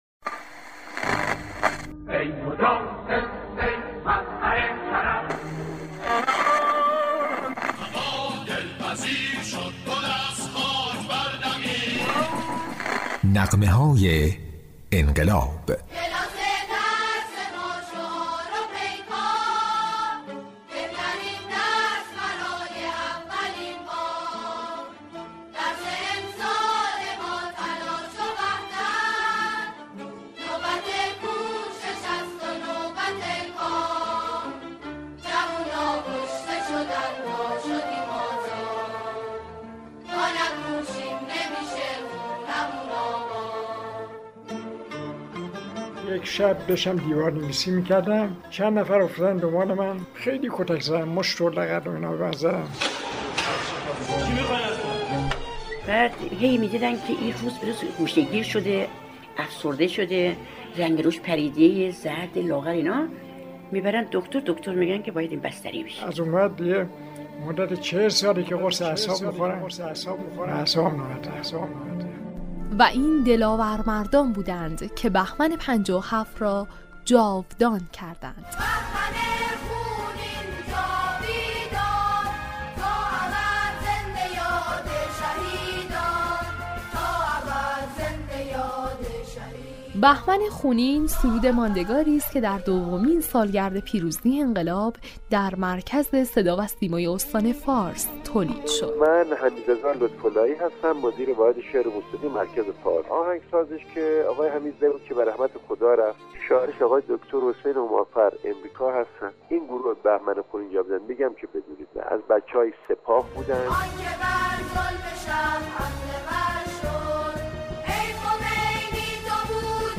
به گزارش شهرآرانیوز، این سرود انقلابی حال‌وهوای روز‌های انقلاب را با هم‌خوانی جمعی از انقلابیون ثبت کرده است.